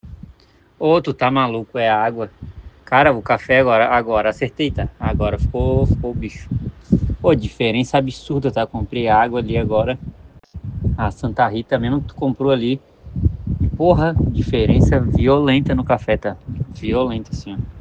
Antes de começarmos o assunto mais técnico, escutem esse relato de um dos nossos assinantes do Clube Arbor.